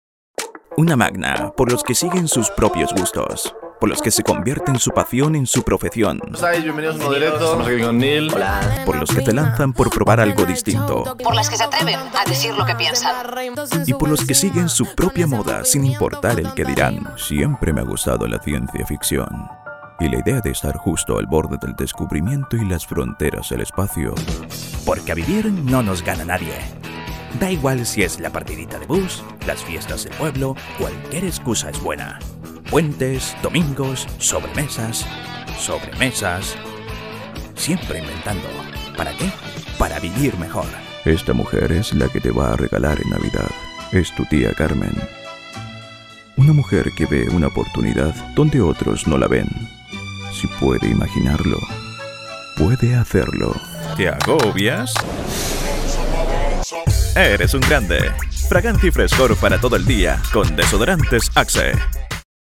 Microfone Neumann TLM 103
Estúdio doméstico
Jovem adulto